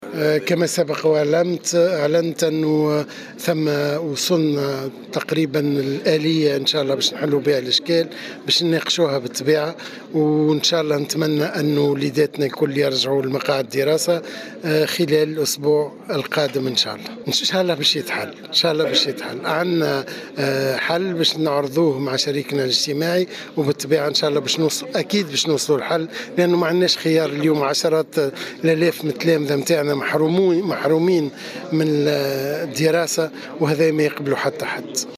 انتظم اليوم السبت، بسوسة الملتقى الثالث من الاجتماعات الوطنية بشأن النقل المدرسي الريفي.
وفي تصريح للجوهرة أف أم، قال وزير التربية فتحي السلاوتي إن عدم توفر النقل الريفي يعد احد اسباب الانقطاع المدرسي مشيرا الى ان اكثر من الف ومئتي مدرسة موجودة في المناطق الريفية .